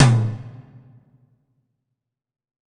WTOM 2.wav